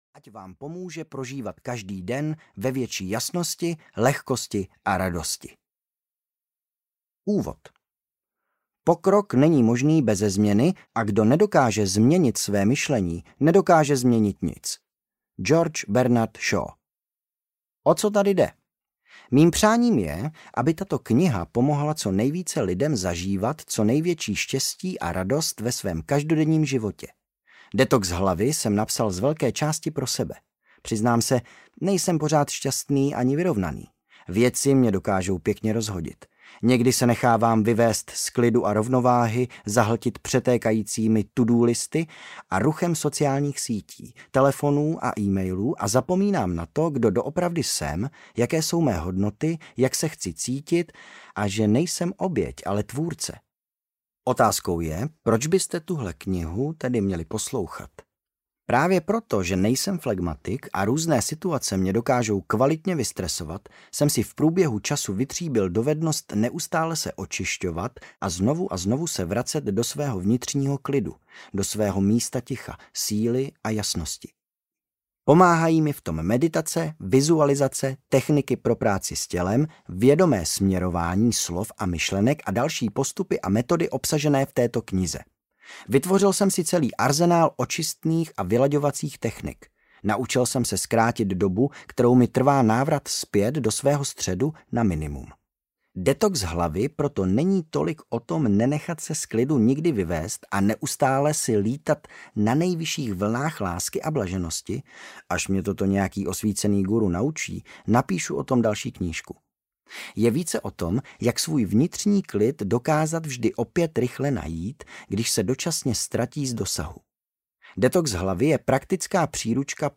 Detox hlavy audiokniha
Ukázka z knihy